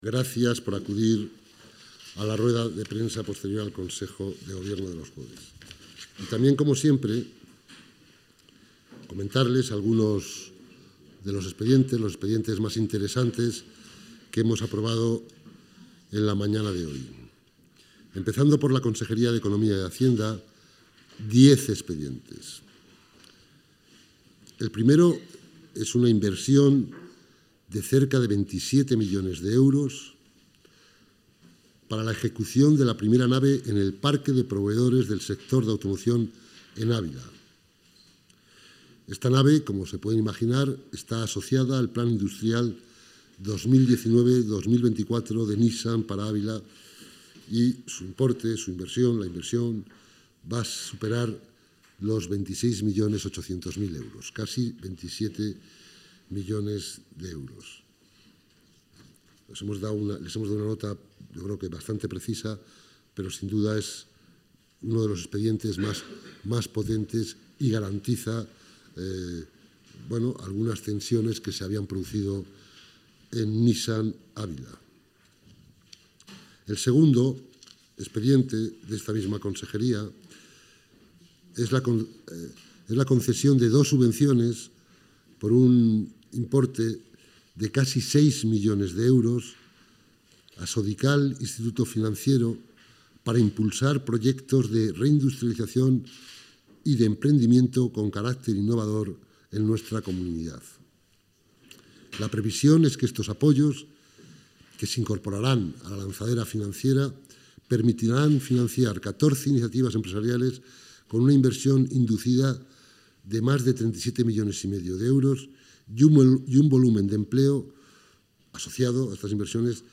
Rueda de prensa posterior al Consejo de Gobierno.